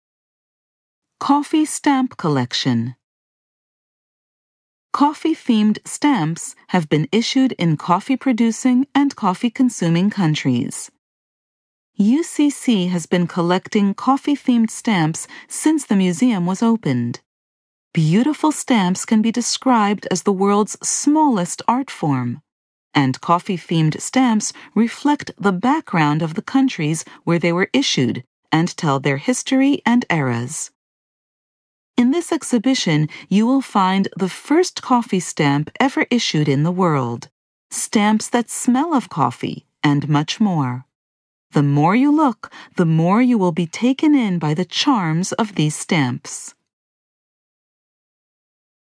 Audio exhibition guide